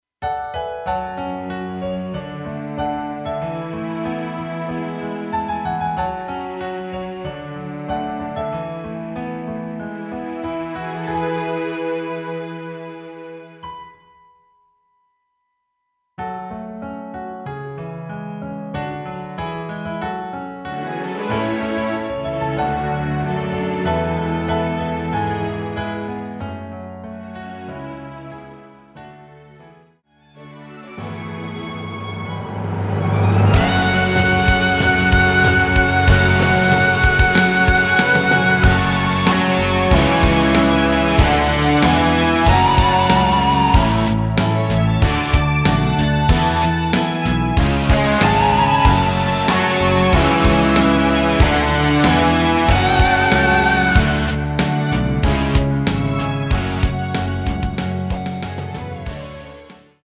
F#m
노래방에서 음정올림 내림 누른 숫자와 같습니다.
앞부분30초, 뒷부분30초씩 편집해서 올려 드리고 있습니다.
중간에 음이 끈어지고 다시 나오는 이유는